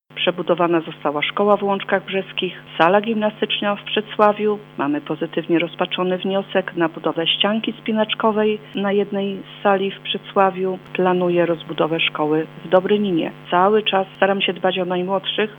Jak mówi burmistrz Przecławia Renata Siembab wszystkie te działania modernizacyjne w placówkach szkolnych mają polepszyć warunki pracy i nauki nie tylko dla nauczycieli ale również dla samych uczniów.